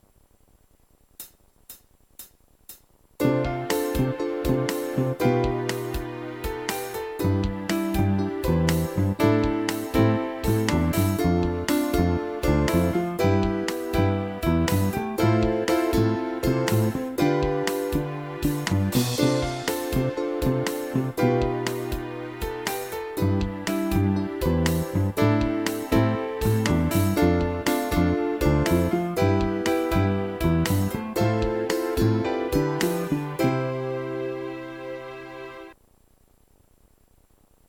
癒し系ＢＧＭ第２弾！のつもりではなかったけど、なんかそれっぽくなってしまった。